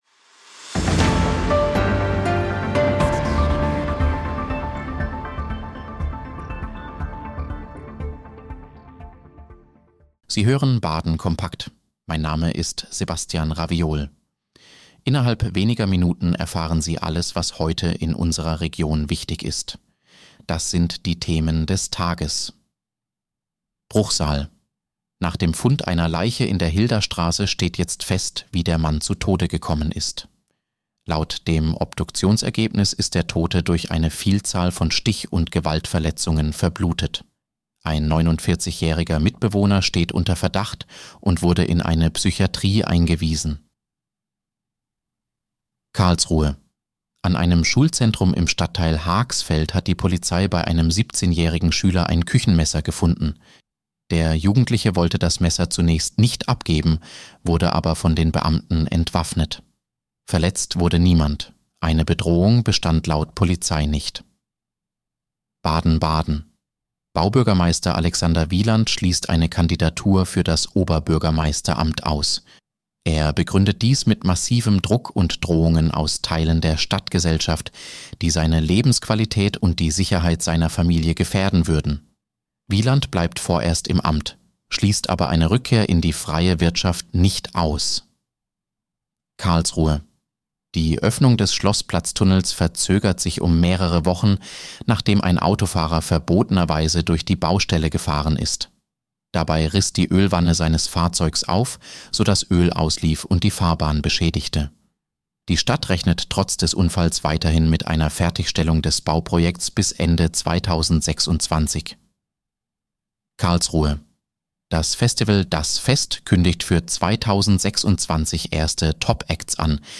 Nachrichtenüberblick Donnerstag, 4. Dezember 2025
Nachrichten